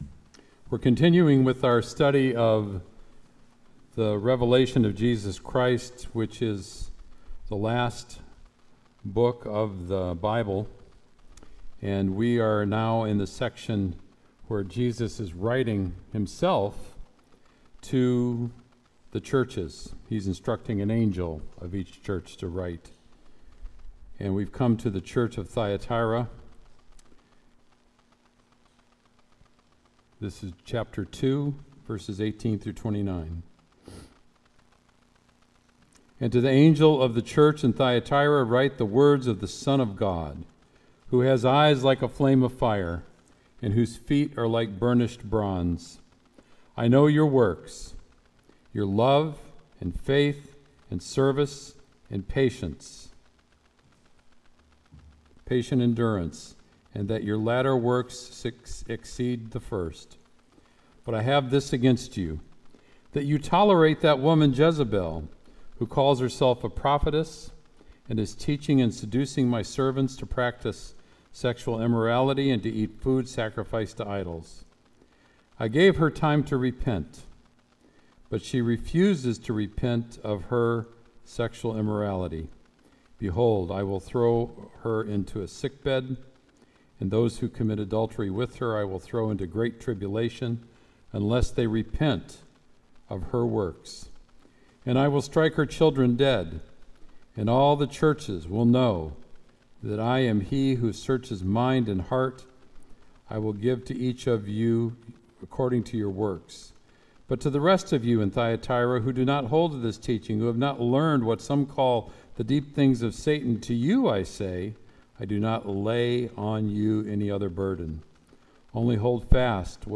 Sermon “Only Hold Fast”